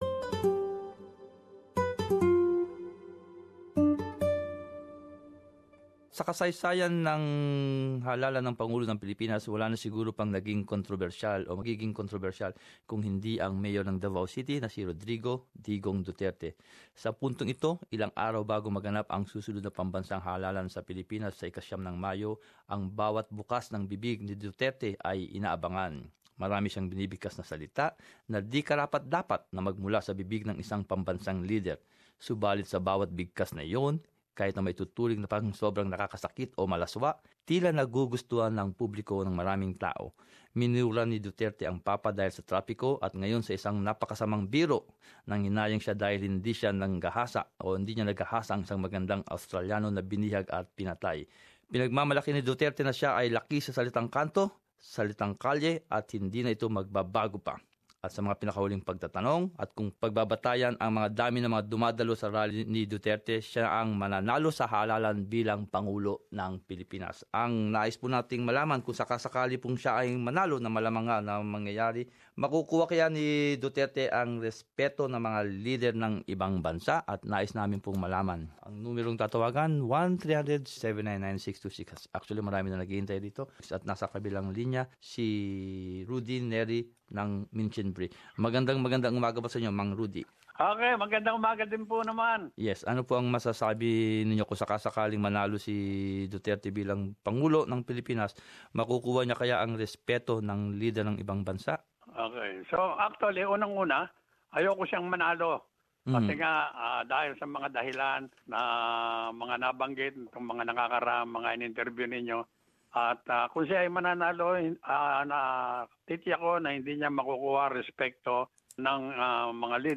In our 'Talkback', several members of the Filipino community in Australia, share their thoughts.